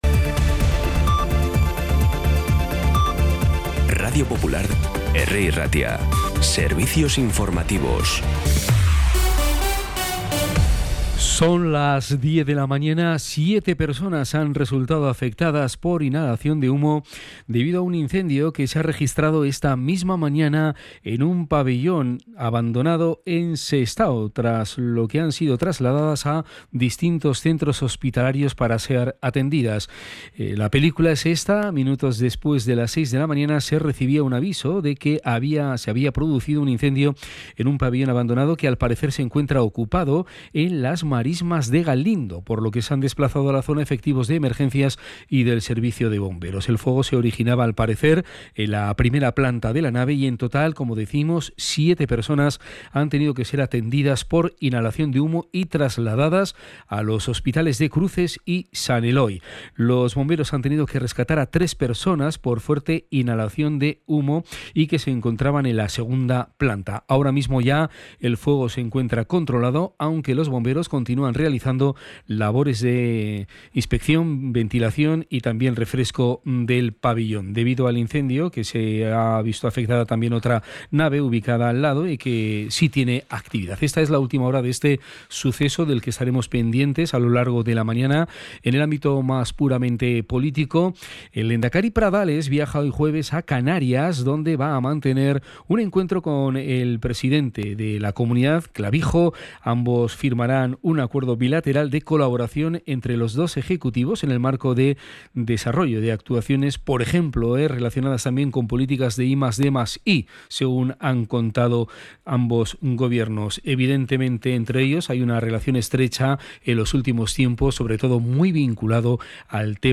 Las noticias de Bilbao y Bizkaia del 11 de septiembre a las 10
Los titulares actualizados con las voces del día.